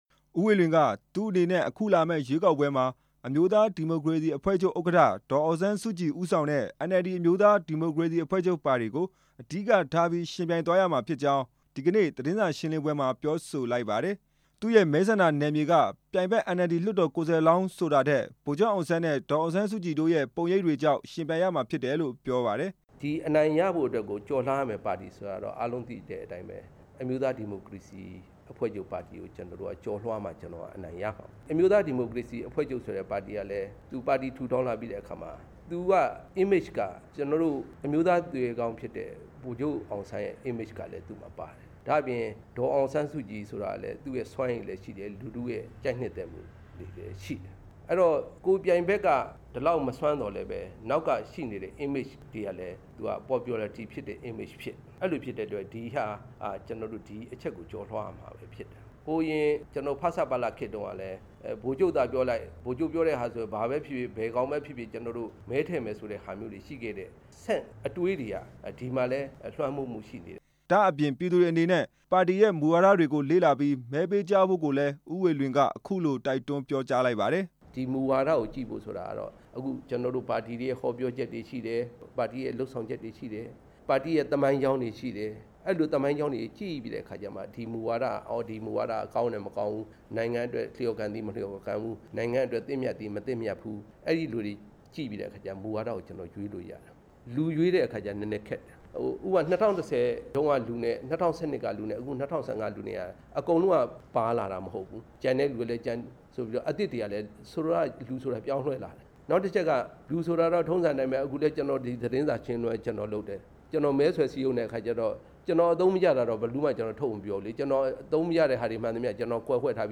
နေပြည်တော် ပုဗ္ဗသီရိမြို့နယ် လွှတ်တော်ကိုယ်စားလှယ်ရုံးမှာ ဒီကနေ့ မနက်ပိုင်းကပြုလုပ်တဲ့ သတင်းစာ ရှင်းလင်းပွဲမှာ ဦးဝေလွင်က ပြောဆိုခဲ့တာဖြစ်ပါတယ်။